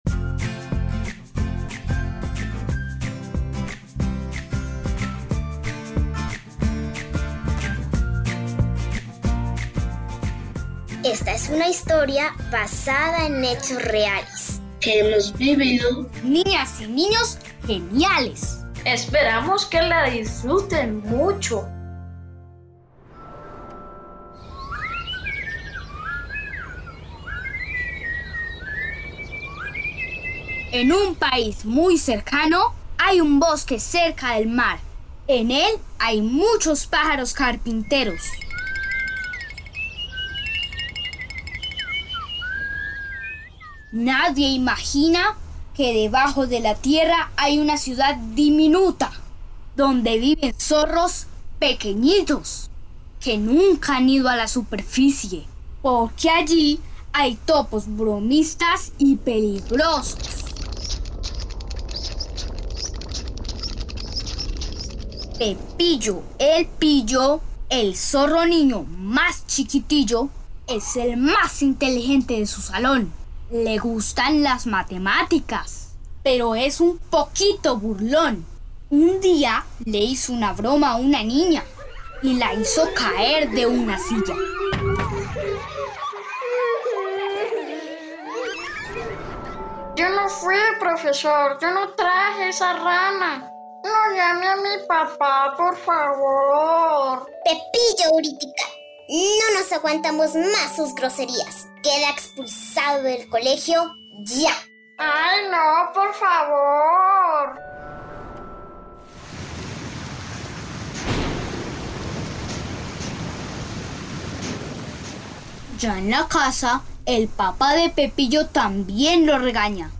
Radiocuento - Pepillo el pillo - Pódcast Geniales para niños | RTVCPlay
GENIA_T01_CUENTOS_EN_RADIOTEATRO_C02_ALTA.mp3